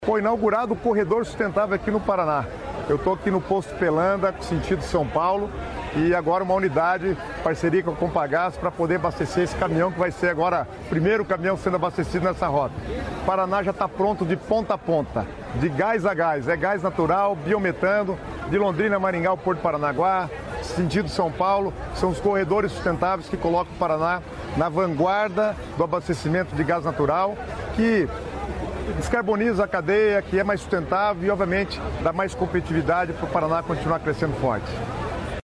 Sonora do secretário de Estado das Cidades, Guto Silva, sobre rede de combustíveis sustentáveis com novo posto de GNV na BR-116